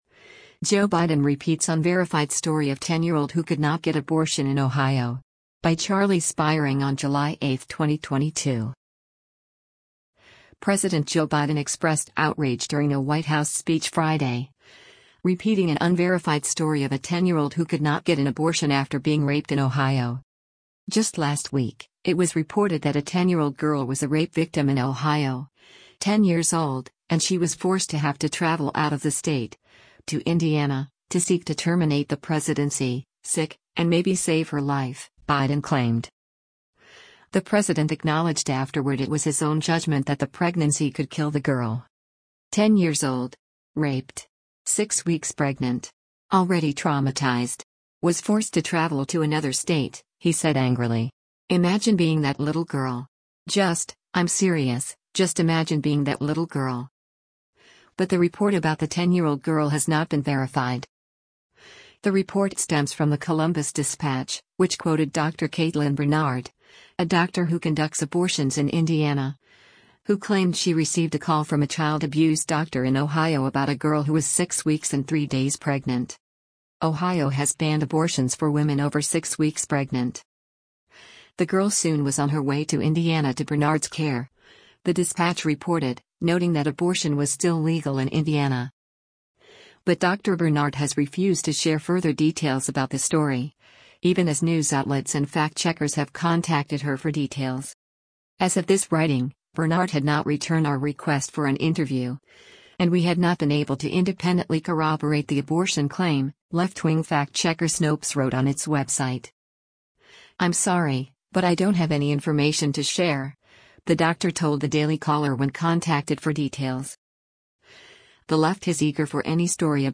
President Joe Biden speaks about abortion access during an event in the Roosevelt Room of
President Joe Biden expressed outrage during a White House speech Friday, repeating an unverified story of a ten-year-old who could not get an abortion after being raped in Ohio.
“Ten years old. Raped. Six weeks pregnant. Already traumatized. Was forced to travel to another state,” he said angrily.